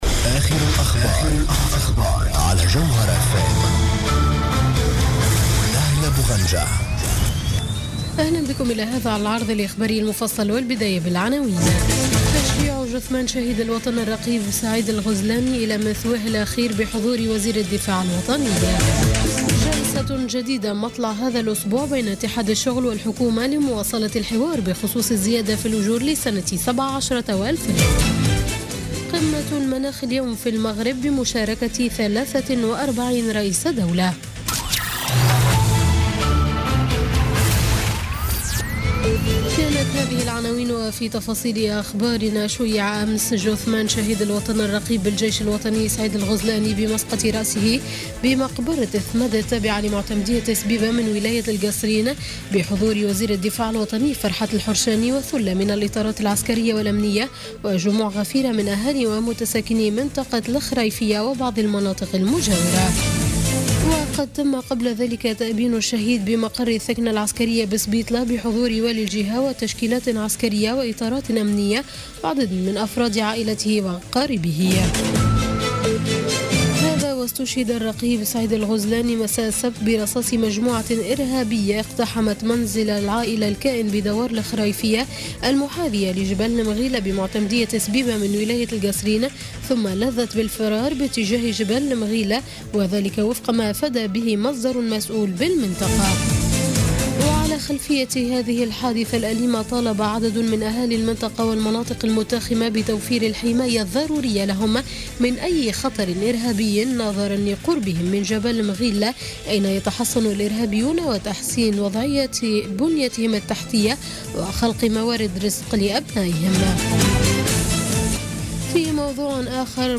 نشرة أخبار منتصف الليل ليوم الإثنين 7 نوفمبر 2016